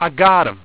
w1_hit.wav